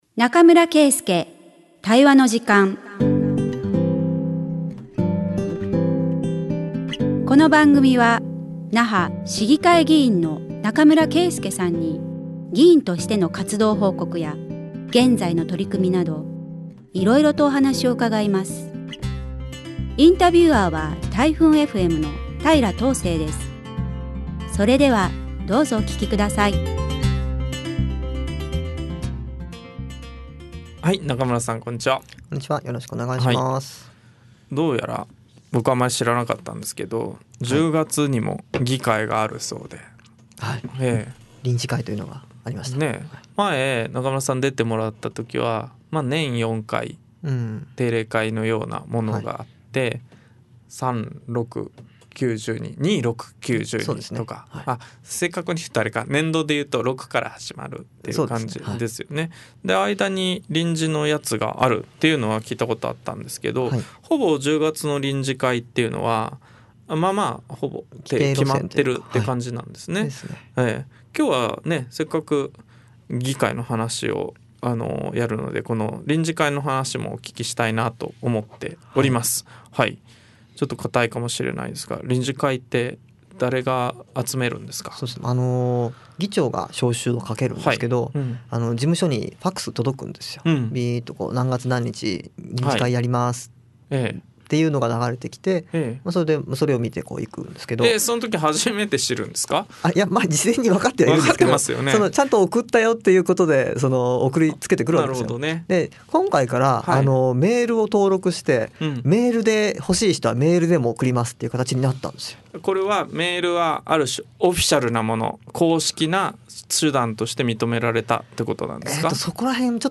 那覇市議会議員中村圭介が議員活動や現在の取組みを語る20分